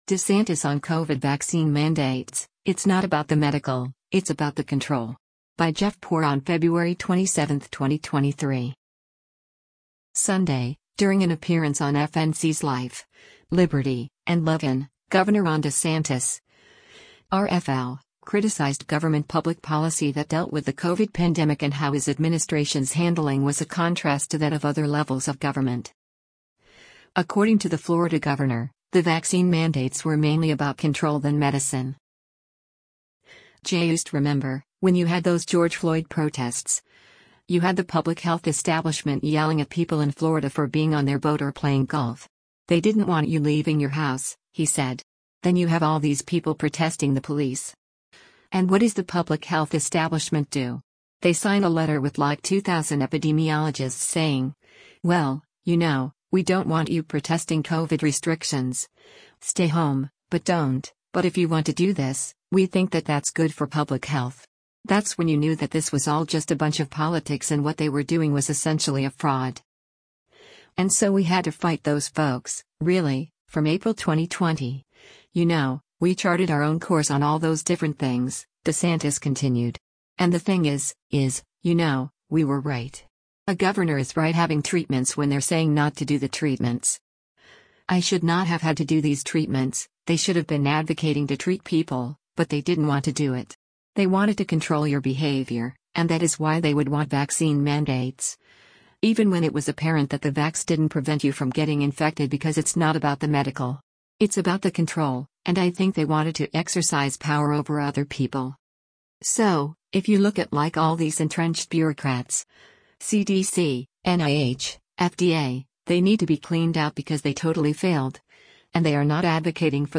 Sunday, during an appearance on FNC’s “Life, Liberty & Levin,” Gov. Ron DeSantis (R-FL) criticized government public policy that dealt with the COVID pandemic and how his administration’s handling was a contrast to that of other levels of government.